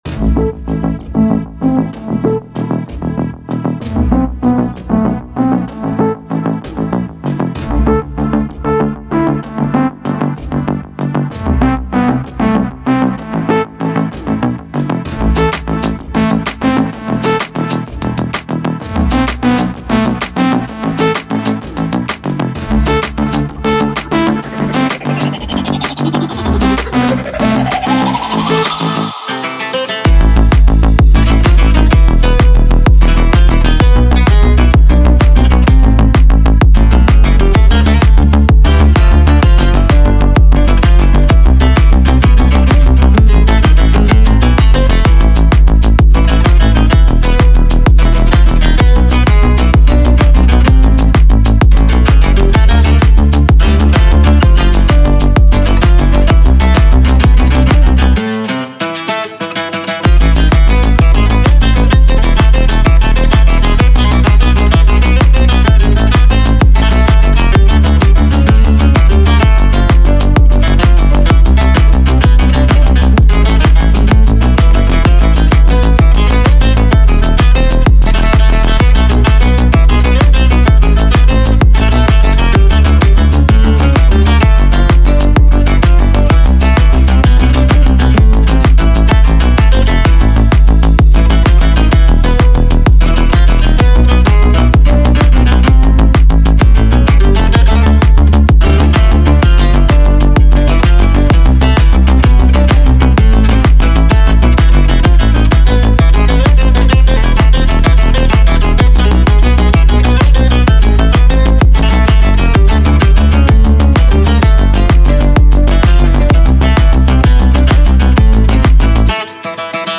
ریمیکسی تازه
تلفیق موسیقی الکترونیک و سنتی
در قالبی متفاوت و ریتمیک بازآفرینی شده است
با فضایی شاد و پرانرژی